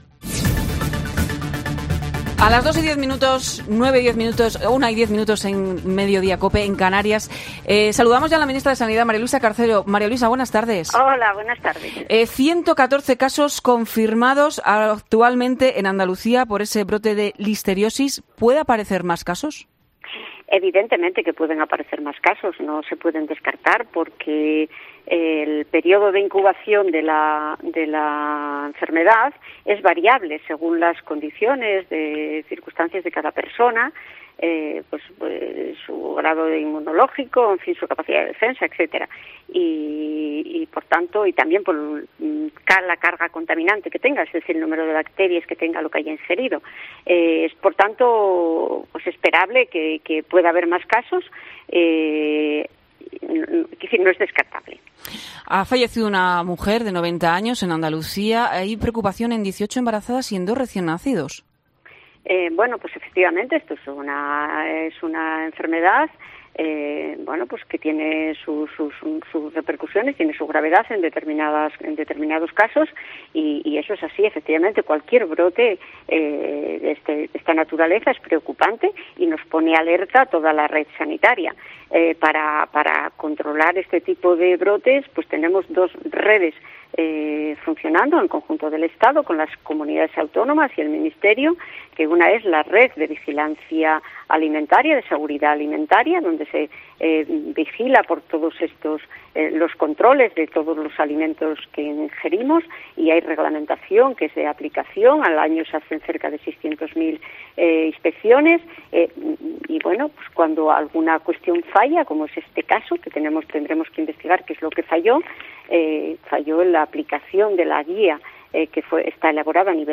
La ministra de Sanidad, en COPE: “Puede haber nuevos casos de listeriosis”